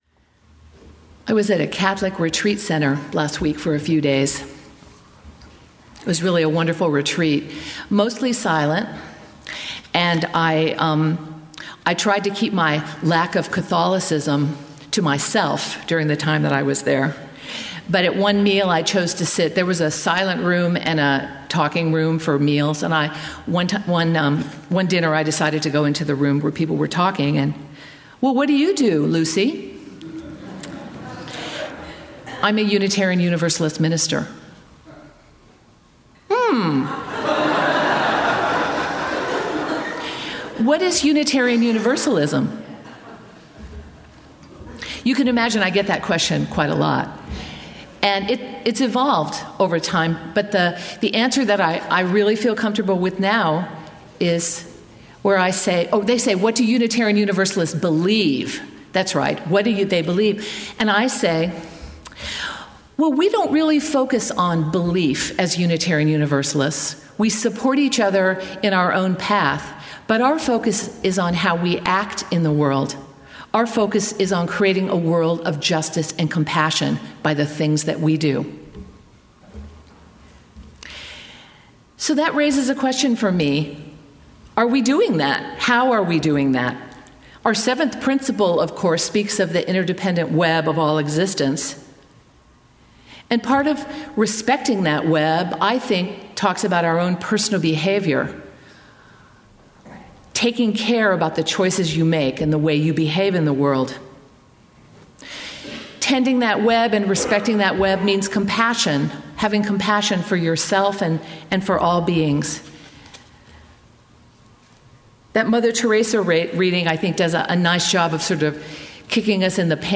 As the world becomes more challenging and our lives more complex, many people act out their frustrations and need for control behind the wheel of a car. Today we will hear from a recovering aggressive driver who will share her story of how she came to terms with her role in the drama on the highway.